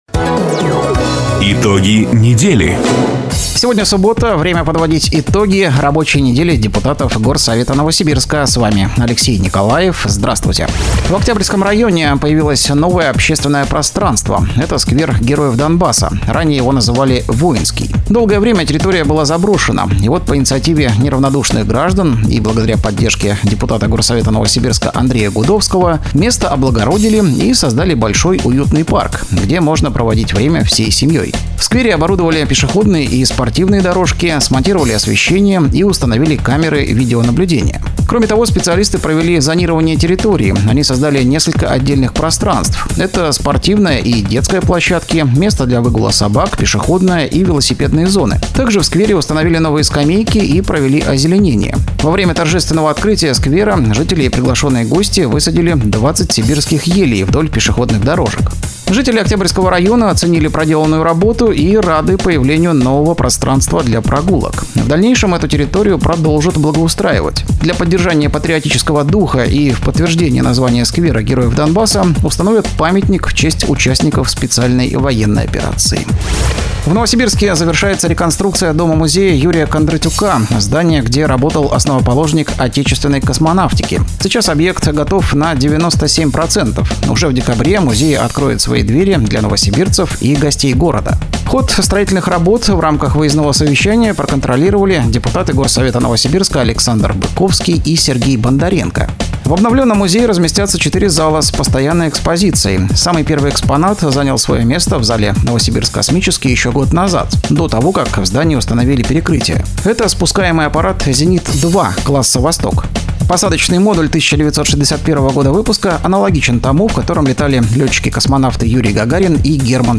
Запись программы "Итоги недели", транслированной радио "Дача" 21 сентября 2024 года.